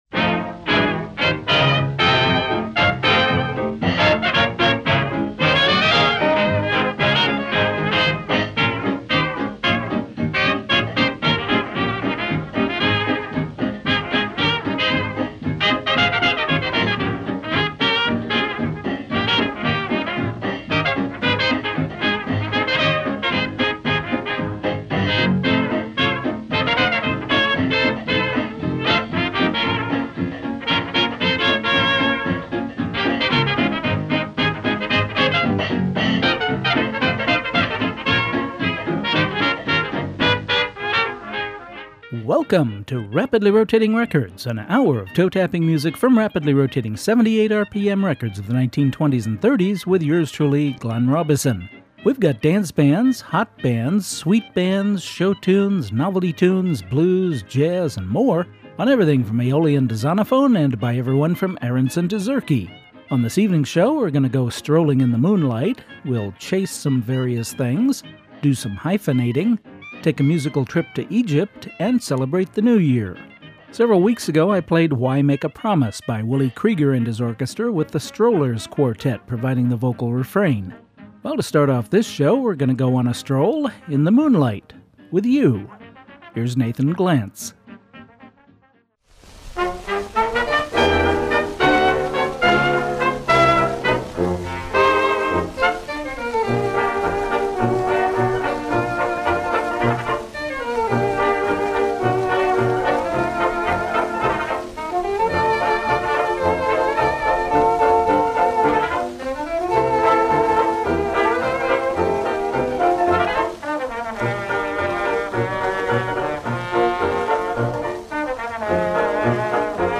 broadcast Sunday 6:00 PM over KISL 88.7 FM Avalon 78 RPM Records 1920s and 1930s